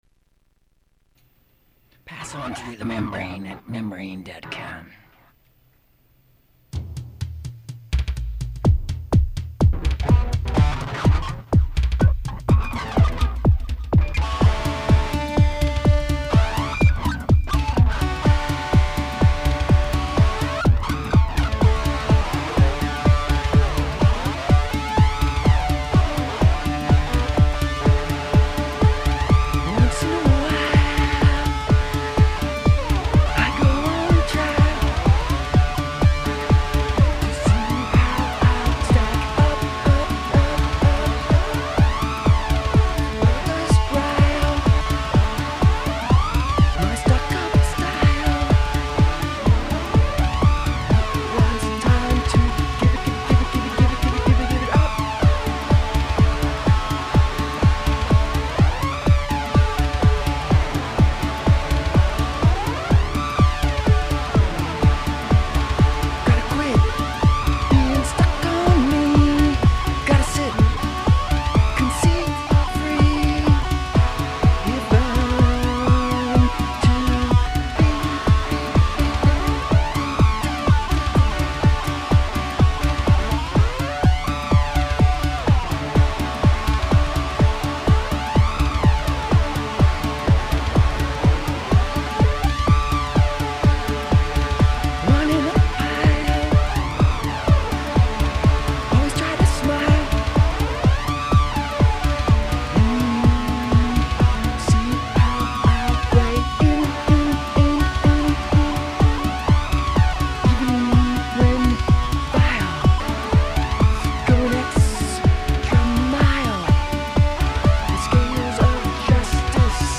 a collection of modern music about the best drug -- air